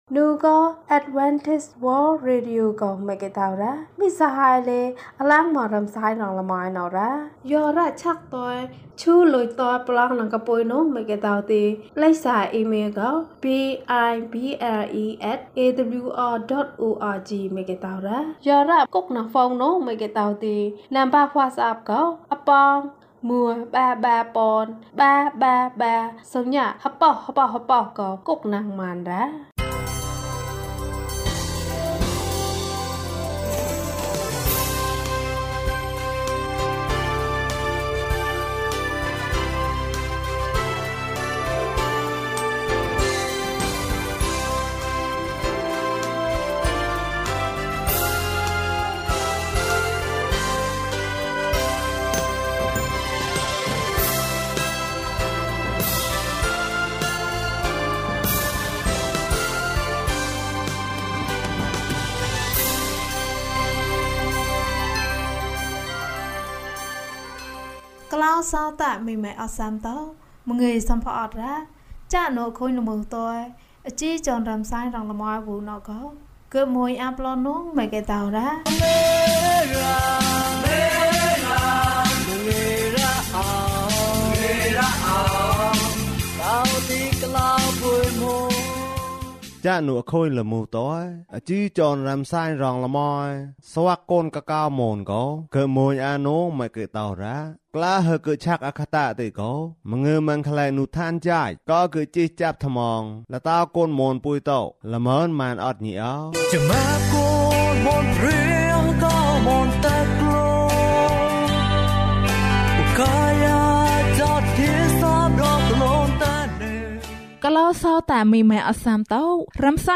မေတ္တာသည် ကျေးဇူးတော်ဖြစ်သည်။ ကျန်းမာခြင်းအကြောင်းအရာ။ ဓမ္မသီချင်း။ တရားဒေသနာ။